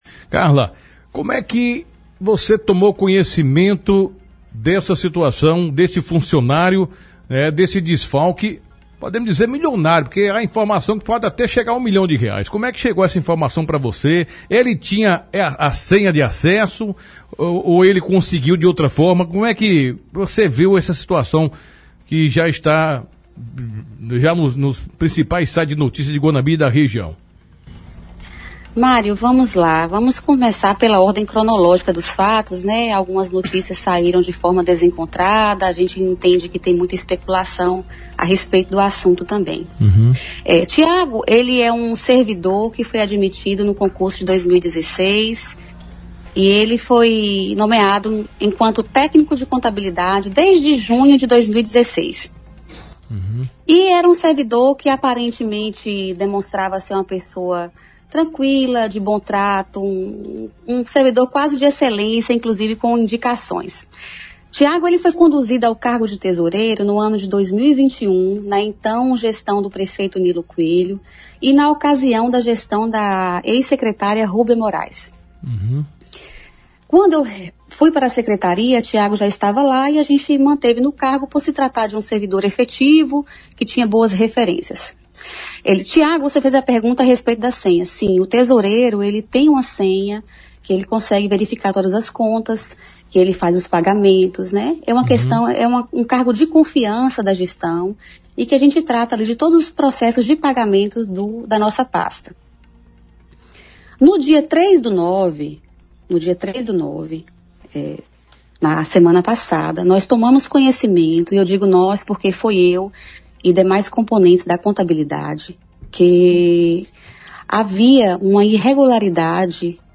A secretária municipal de Assistência Social de Guanambi (BA), Carla Maria, se pronunciou nesta semana sobre o caso envolvendo um servidor acusado de desviar recursos da pasta. A entrevista foi concedida ao programa “Datenão”, da rádio 96 FM.